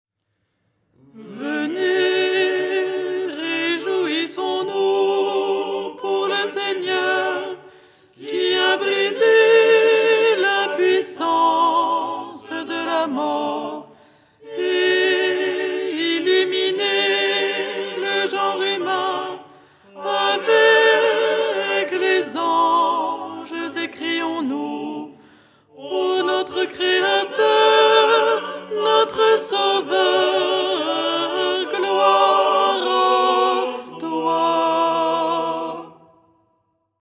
Les tons byzantino-slaves en français
Ton 7
Alto
ton7-04-alto.mp3